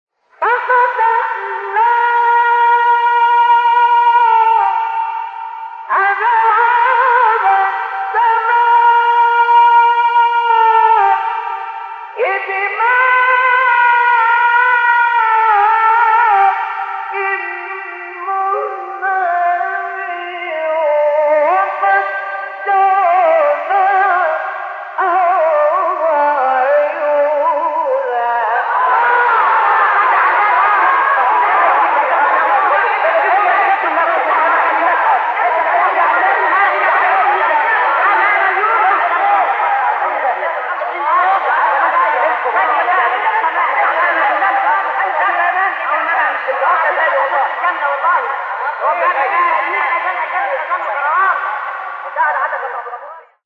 آیه 10-12 سوره قمر استاد مصطفی اسماعیل | نغمات قرآن | دانلود تلاوت قرآن